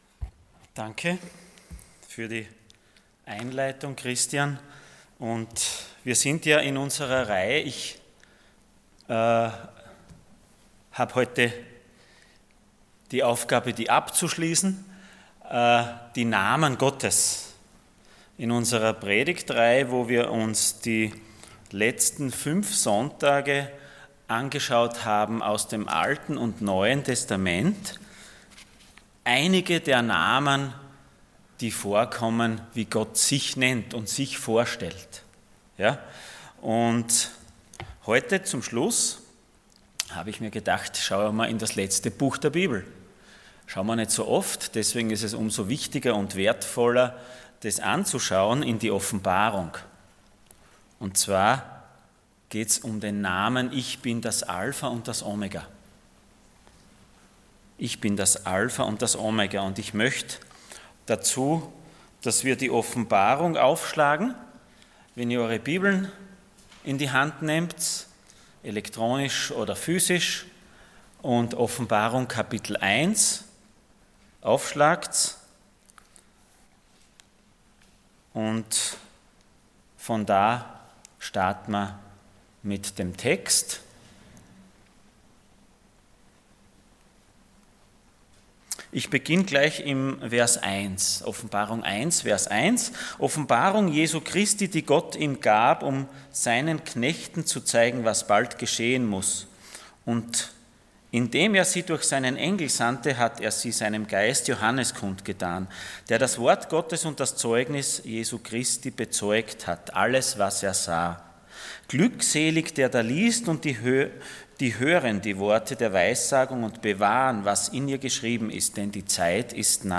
Serie: Die Namen Gottes Passage: Revelation 1:1-8 Dienstart: Sonntag Morgen %todo_render% Alpha und Omega « Der Retter